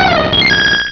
Cri de Mentali dans Pokémon Diamant et Perle.